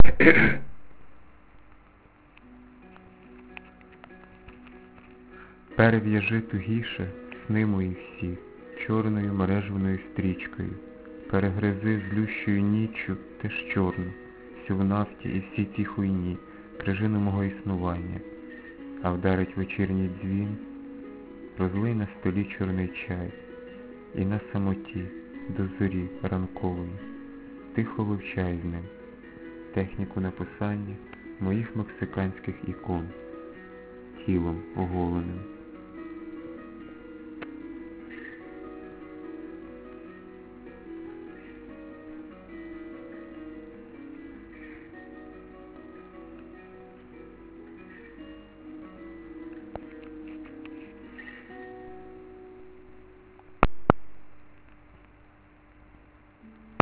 навіть шуми чи другорядні звуки вгризаються в шкіру